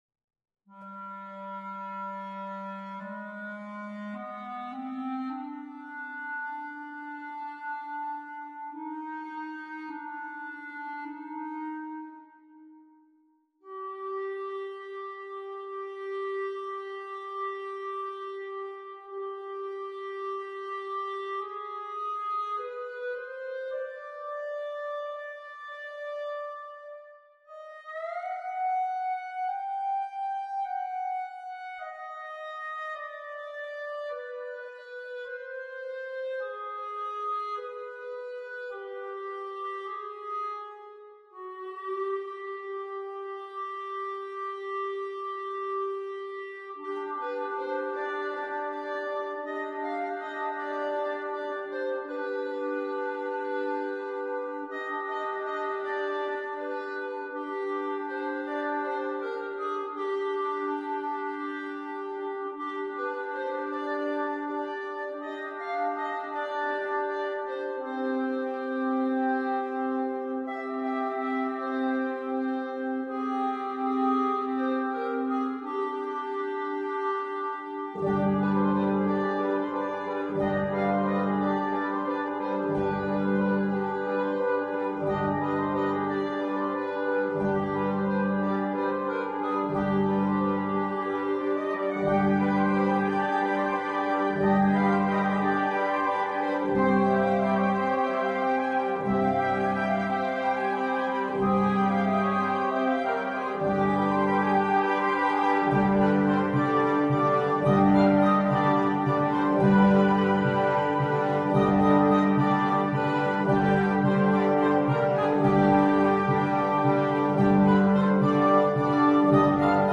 Suite in tre movimenti su temi della Sicilia Medievale
MUSICA PER BANDA
Un grande affresco musicale